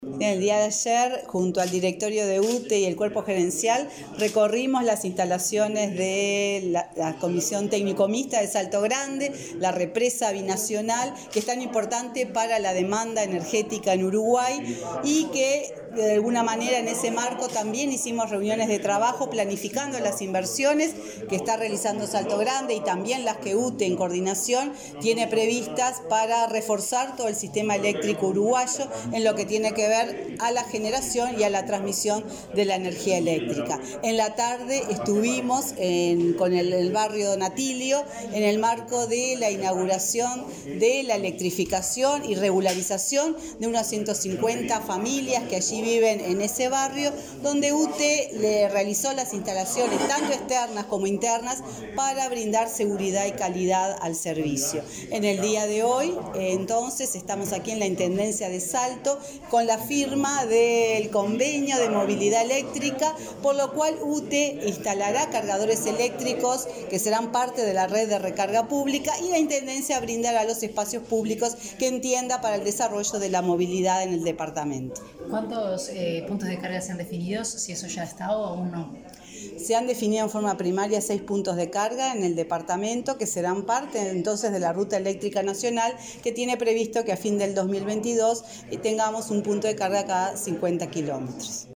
Declaraciones a la prensa de la presidenta de UTE
Declaraciones a la prensa de la presidenta de UTE 26/10/2021 Compartir Facebook Twitter Copiar enlace WhatsApp LinkedIn La titular de UTE, Silvia Emaldi, visitó este martes 26 el departamento de Salto, donde firmó dos acuerdos con el intendente Andrés Lima, y, luego, dialogó con la prensa.